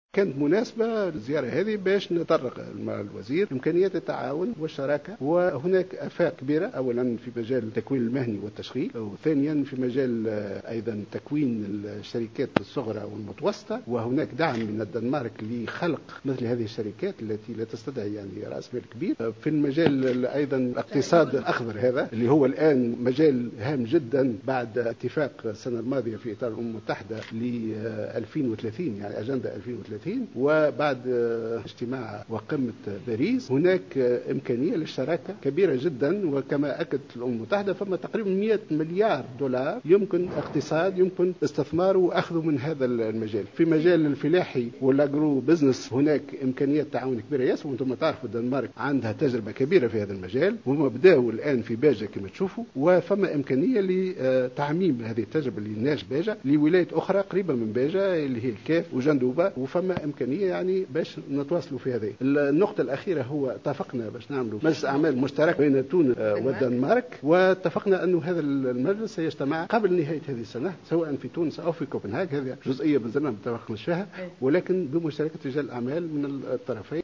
وقال الجيهناوي في تصريح